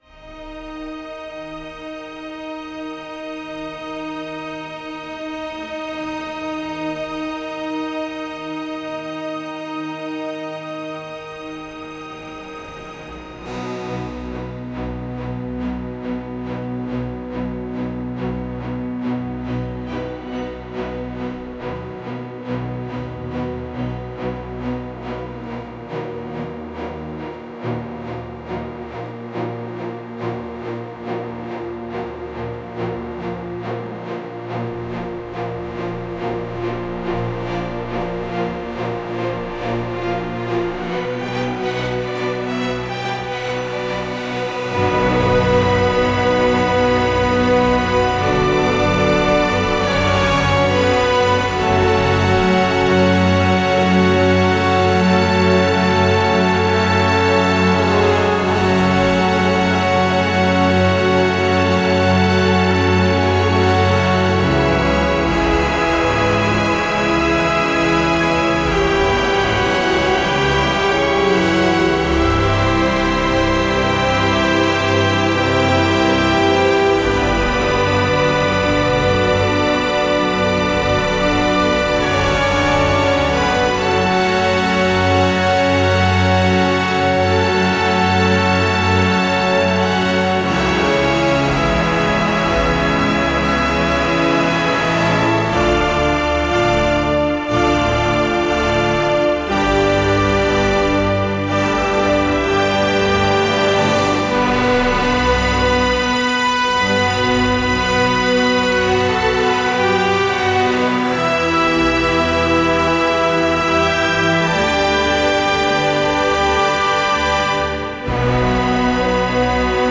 супергеройски-космическая мелодия.aac